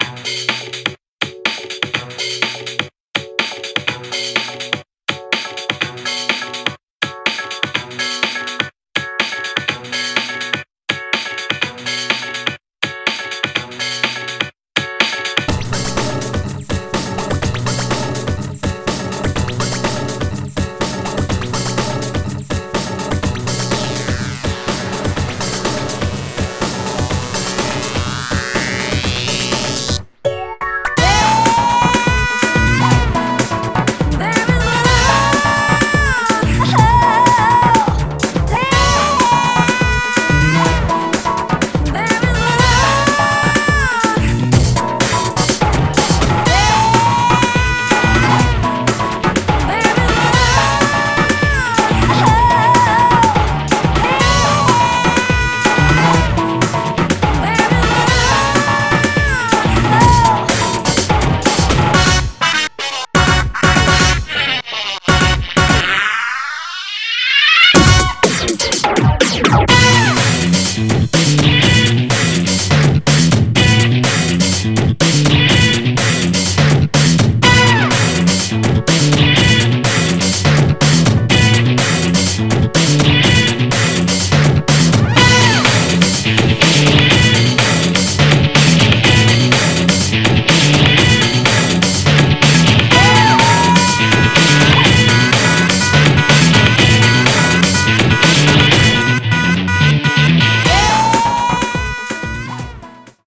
BPM124-248
Audio QualityPerfect (High Quality)